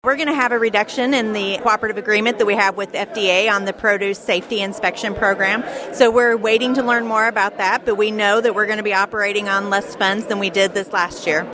During her remarks at KMMO Ag Day on the Mull Family Farm outside Malta Bend on Thursday, March 13, Missouri Director of Agriculture Chris Chinn updated attendees on a pair of developments from Washington.